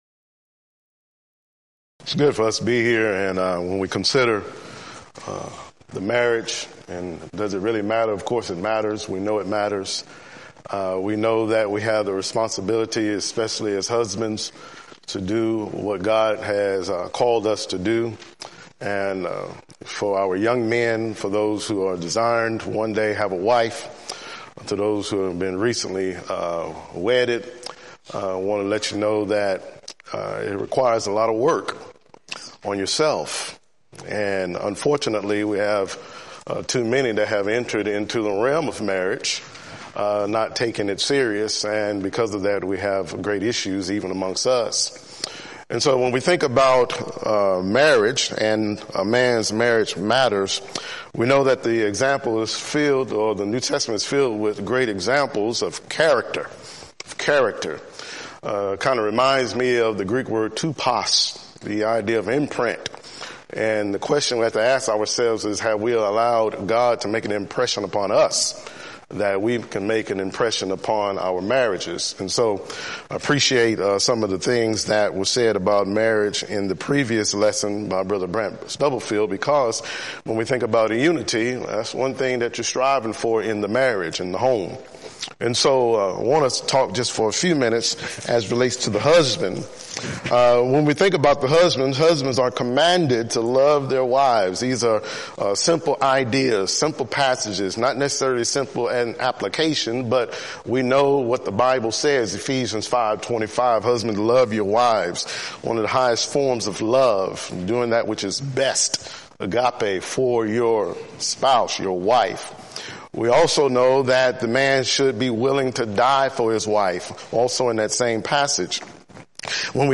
Event: 6th Annual BCS Men's Development Conference
lecture